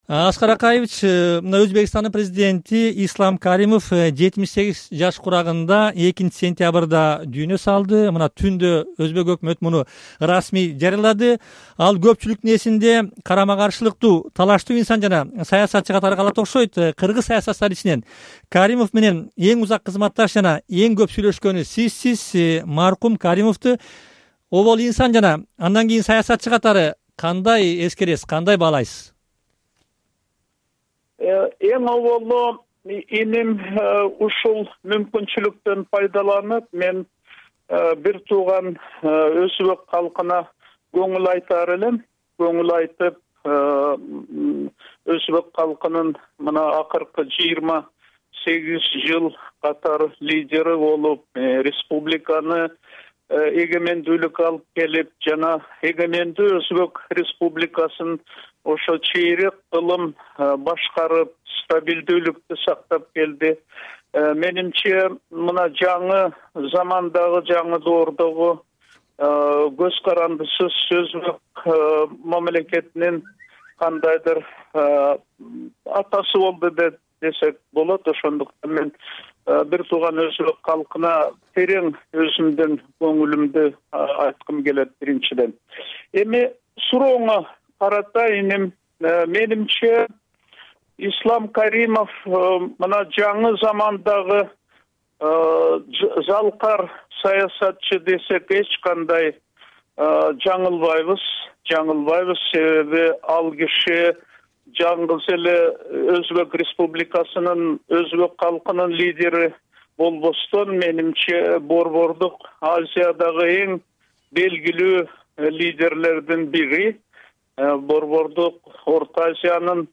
Кыргызстандын биринчи президенти, учурда Москвада жашаган Аскар Акаев "Азаттык" радиосу менен маегинде Өзбекстандын дүйнөдөн өткөн президенти Ислам Каримовду инсан жана саясатчы катары эскерип, ал өзү бийликте турган кездеги Бишкек-Ташкент мамилеси боюнча суроолорго да жооп берди.